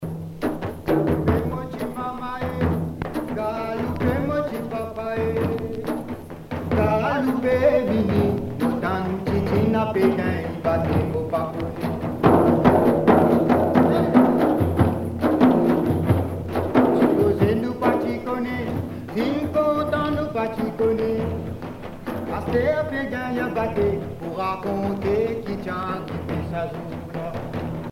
danse
Pièce musicale éditée